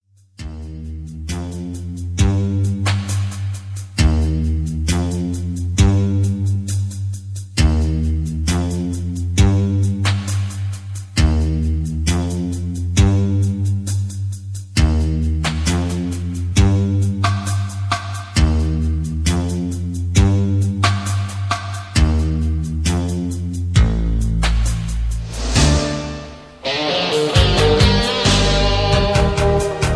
Key Of G